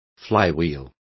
Complete with pronunciation of the translation of flywheel.